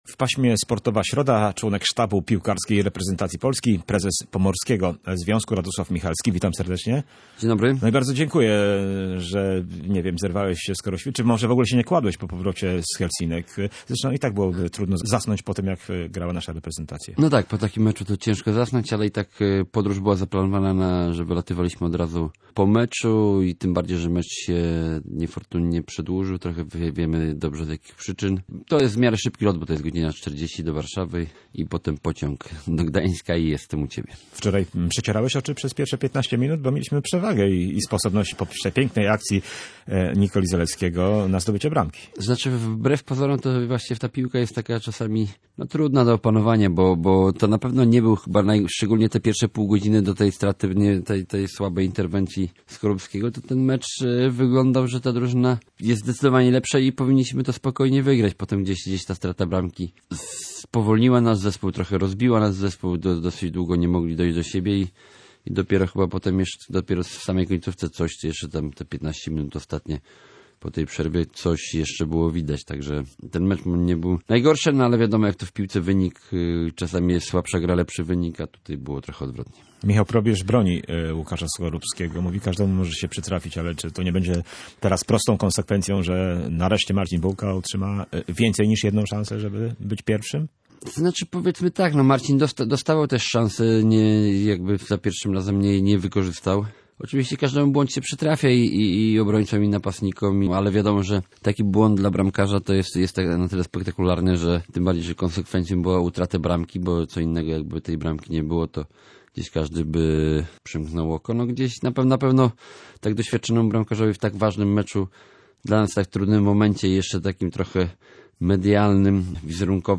rzucił w paśmie Sportowa Środa w Radiu Gdańsk członek reprezentacyjnego sztabu